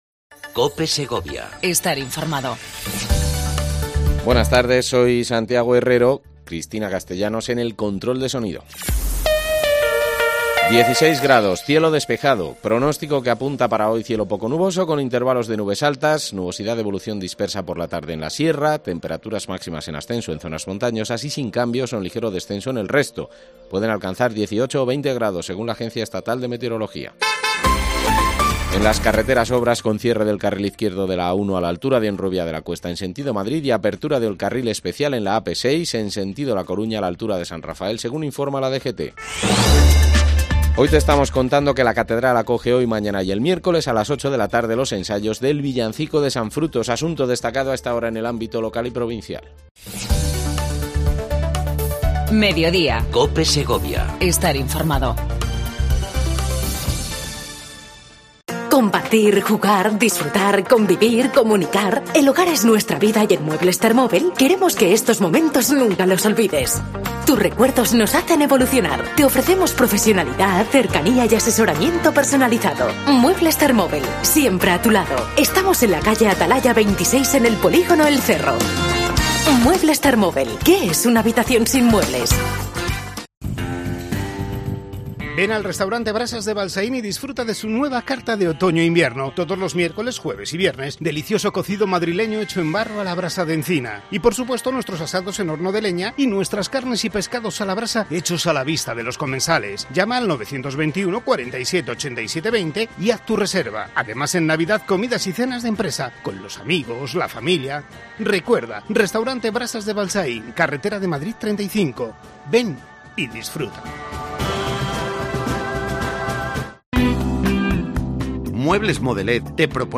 AUDIO: Entrevista a la subdelegada del gobierno en la provincia, Lirio Martín.